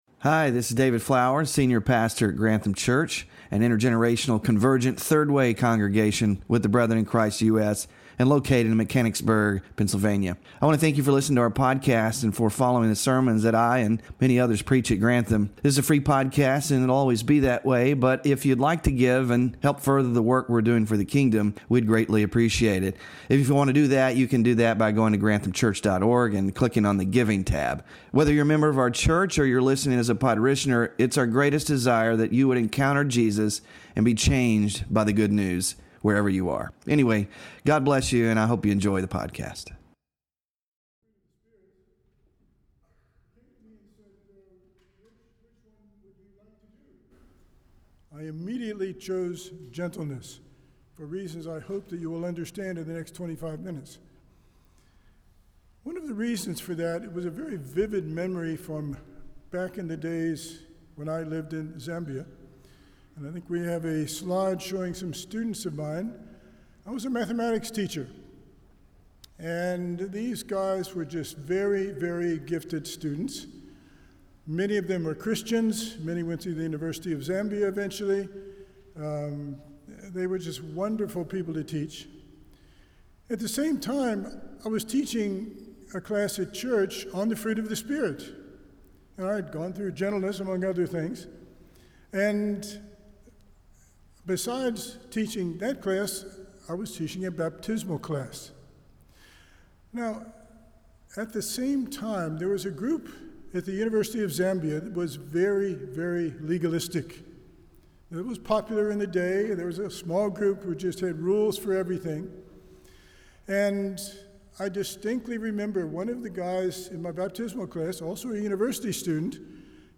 FRUIT OF THE SPIRIT WK8-GENTLENESS SERMON SLIDES